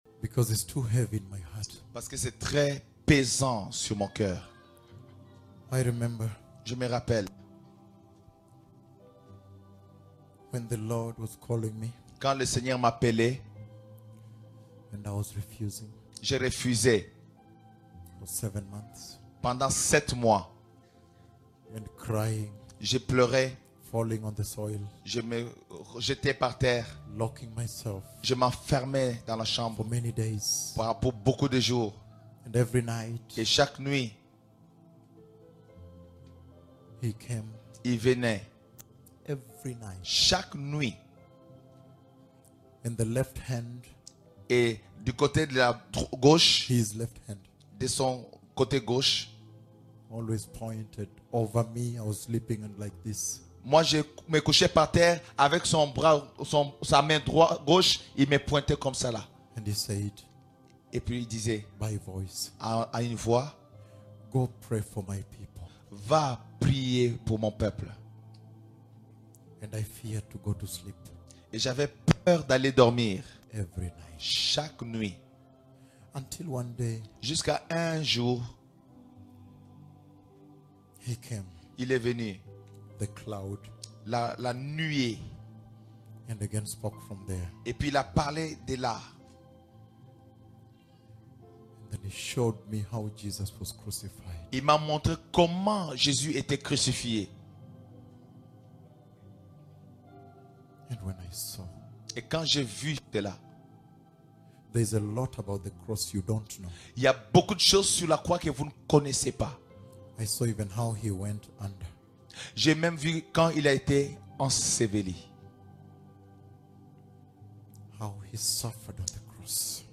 LE SACRIFICE ET LA SOUFFRANCE DE JÉSUS À LA CROIX - ENSEIGNEMENT PRÉSENTÉ PAR LES PUISSANTS PROPHÈTES DE L'ÉTERNEL